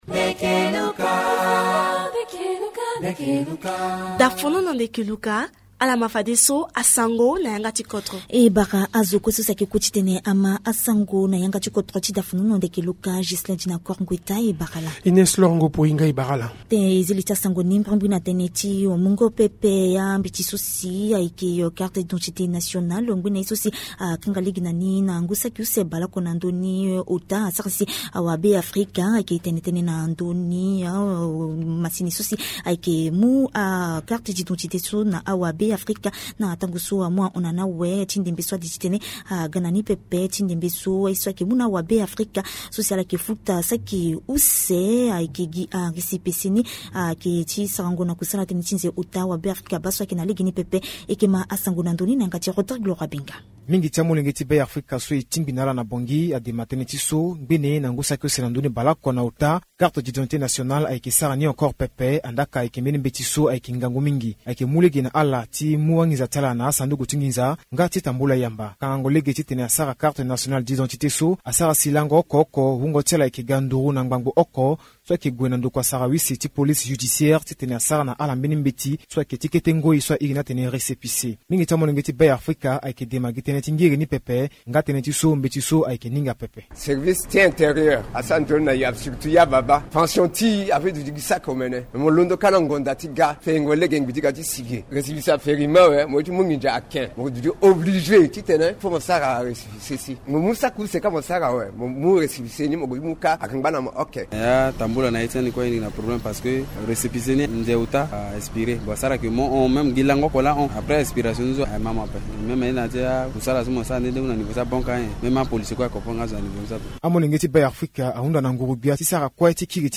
Journal Sango